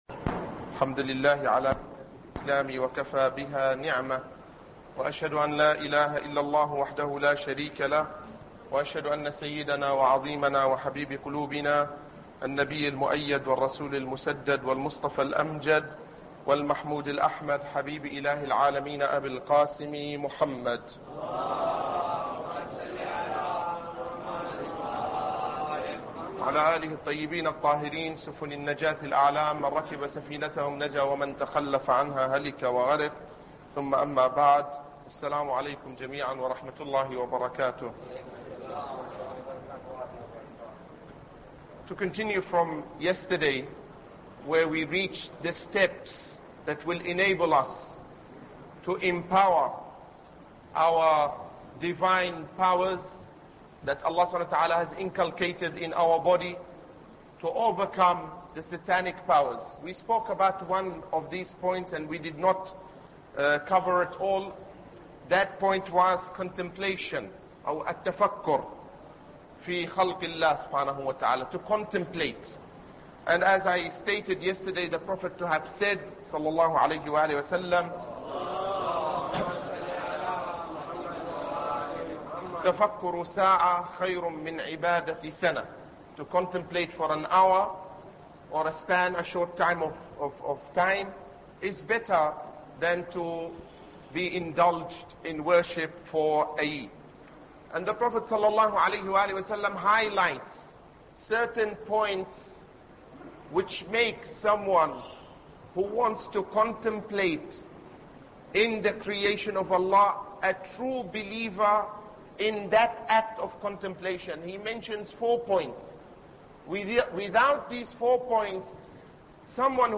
Ramadan Lecture 4